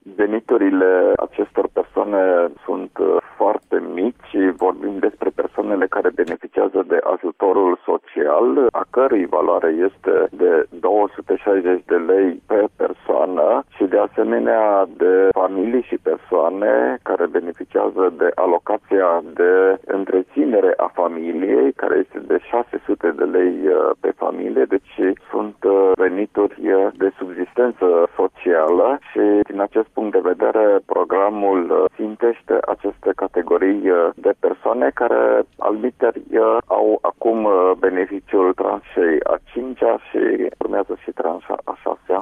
Invitat la Radio România Actualităţi, ministrul Investiţiilor, Marcel Boloş, a amintit cine sunt beneficiarii acestor măsuri de sprijin: